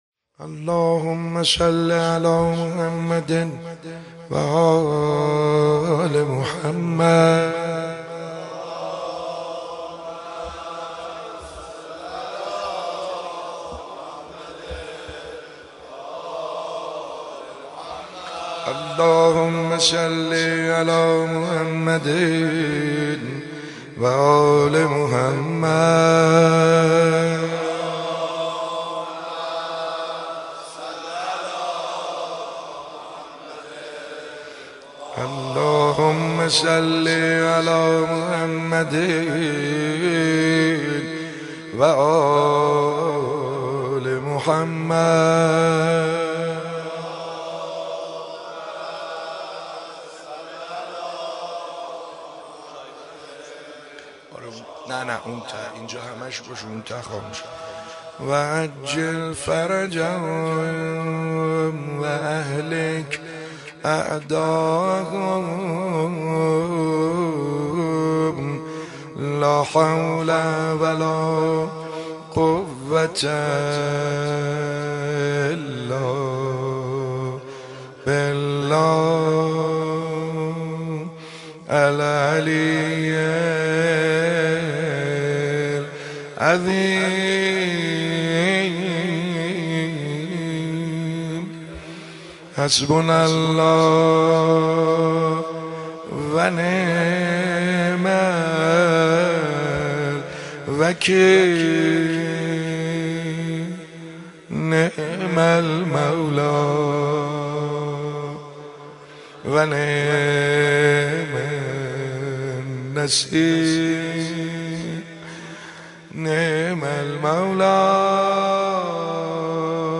زیارت عاشورا 13 اذر مهدیه امام حسن مجتبی(ع)
با مداحی حاج سعید حدادیان برگزار شد